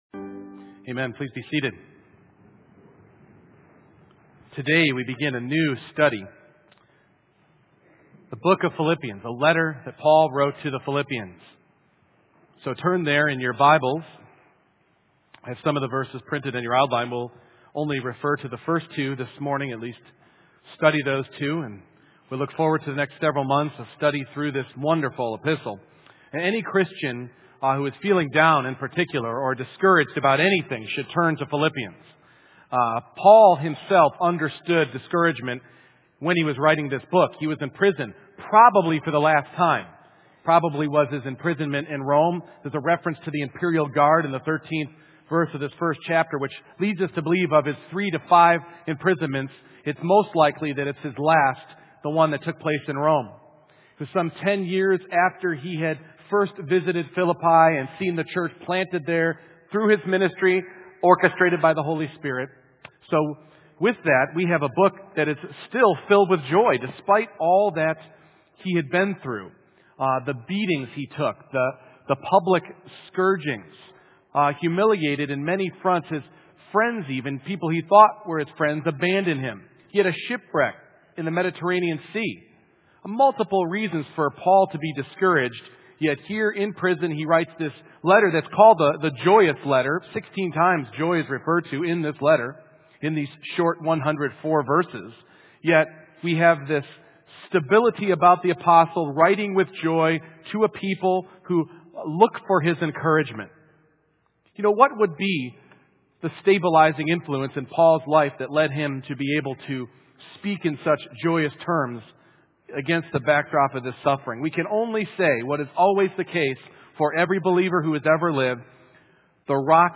Passage: Philippians 1:1-2 Service Type: Morning Worship